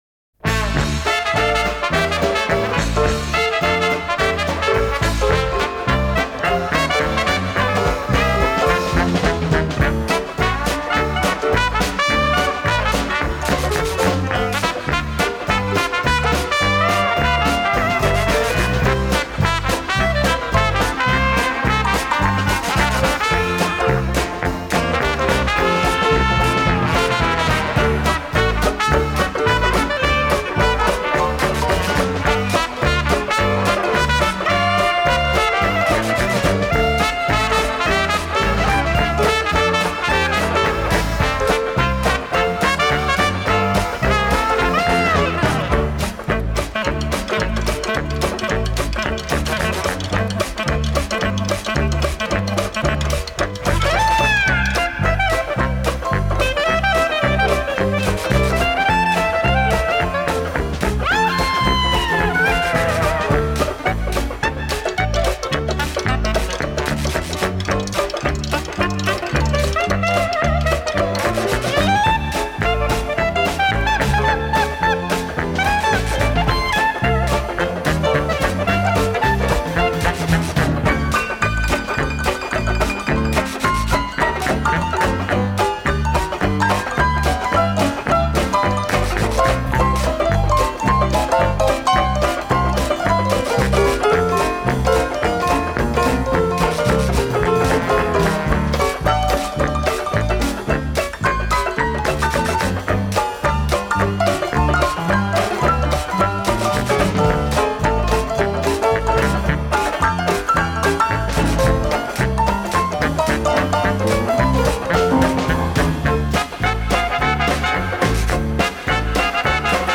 Дикси 3